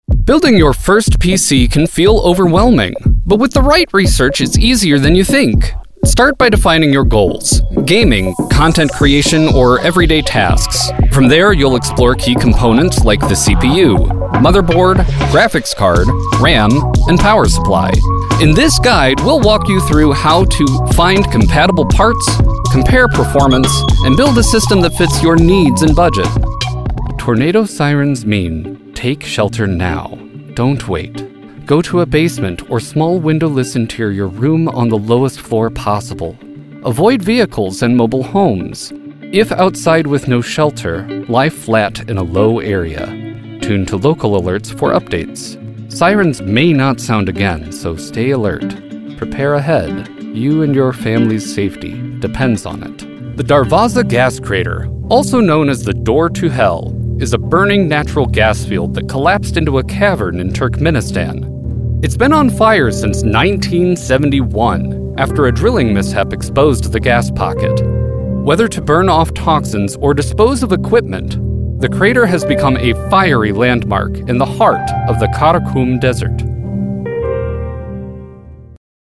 I offer you sincerity, humor, and earnestness across a spectrum of sound from genuine to goofy, opera to metal, corporate to coffee shop, and a few things in between.
Narration Demo